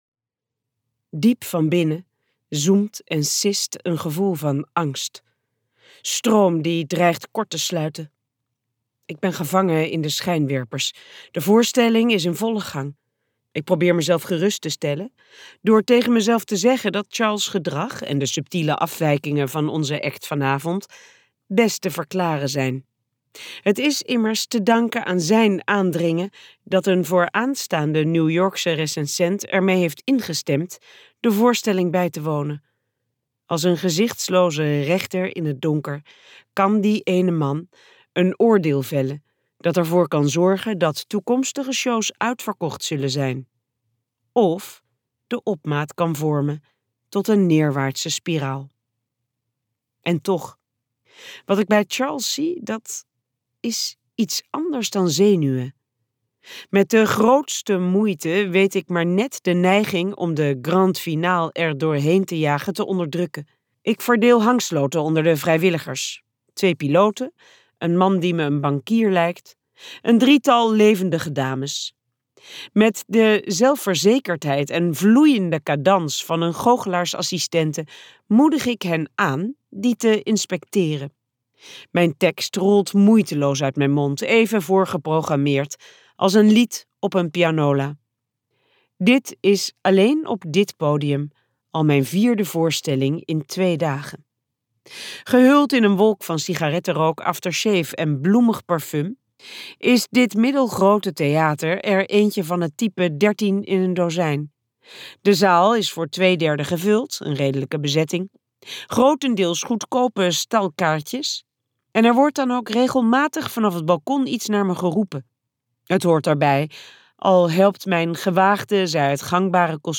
KokBoekencentrum | De kunst van het ontsnappen luisterboek